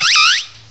cry_not_snivy.aif